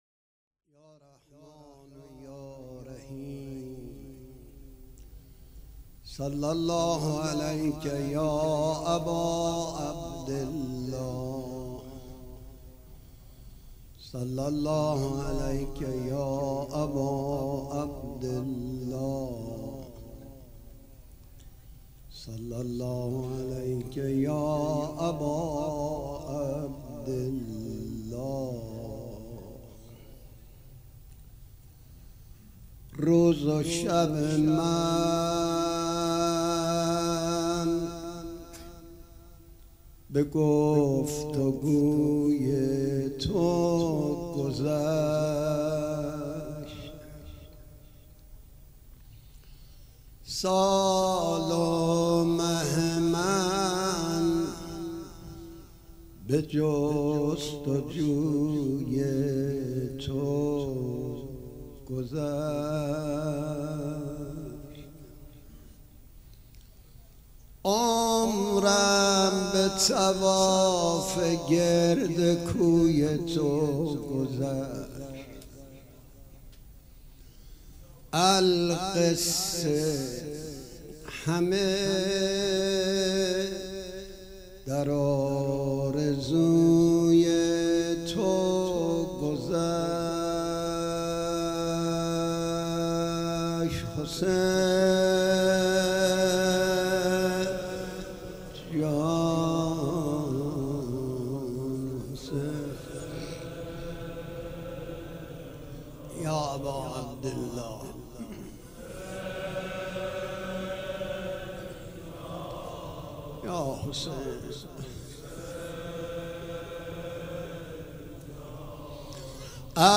پیش منبر
مراسم عزاداری شب پنجم